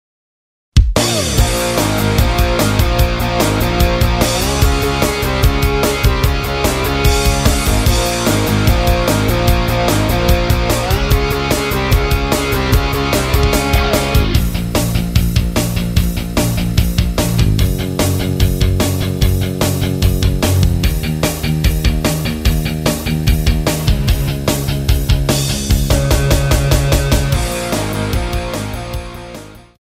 MR입니다. 키 Bbm 가수
원곡의 보컬 목소리를 MR에 약하게 넣어서 제작한 MR이며